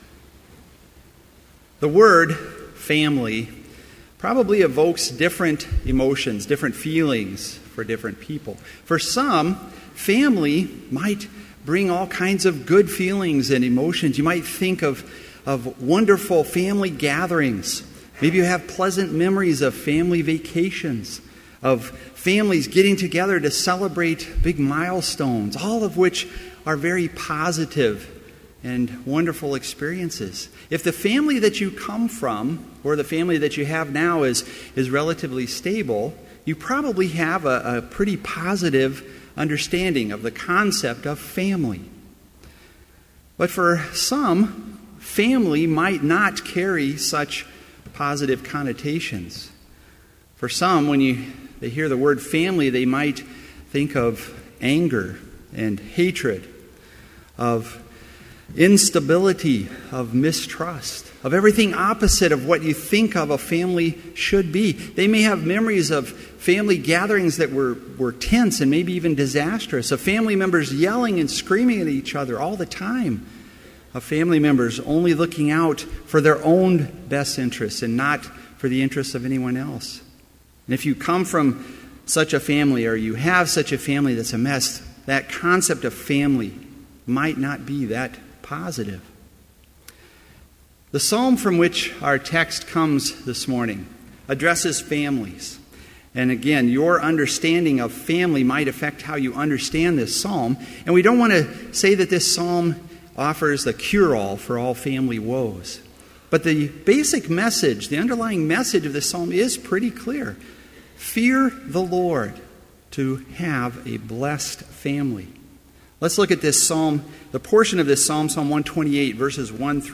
Complete Service
• Prelude
• Homily
This Chapel Service was held in Trinity Chapel at Bethany Lutheran College on Friday, January 24, 2014, at 10 a.m. Page and hymn numbers are from the Evangelical Lutheran Hymnary.